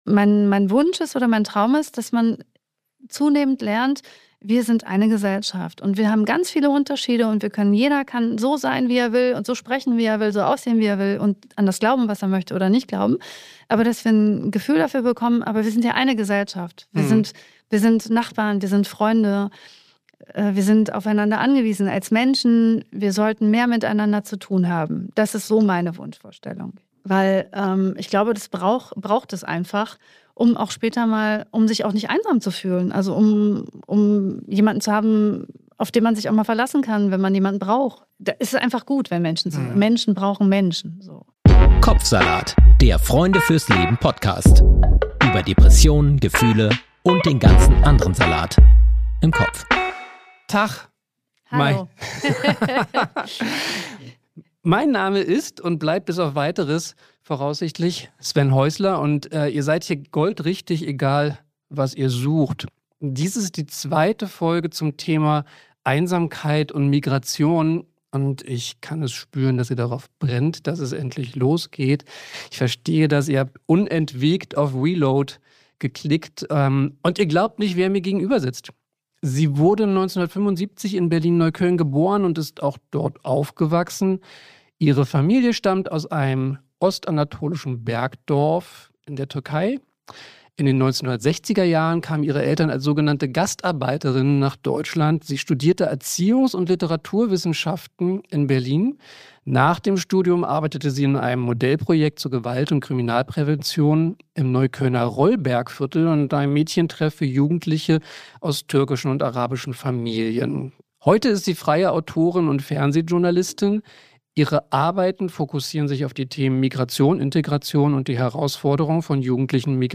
In der zweiten Folge zum Thema Liebeskummer spricht Moderator
mit der systemischen Paartherapeutin